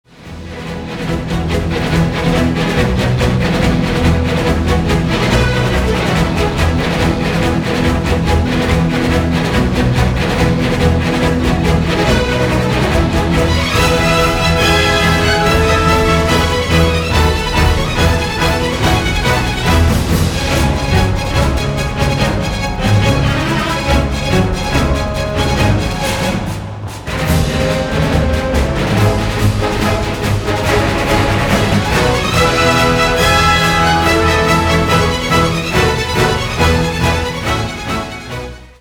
веселые
без слов
инструментальные
OST
бодрые
оркестр